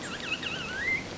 A bird that never tires of the sound of his own voice, the Peppershrike melodic, but monotonous phrases ring out minute after minute, hour after hour throughout the year wherever the species is present.
Different call PROCOSARA, PN San Rafael